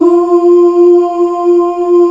Choir(4)_F4_22k.wav